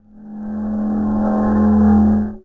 Noise04L.mp3